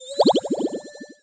potion_bubble_effect_brew_05.wav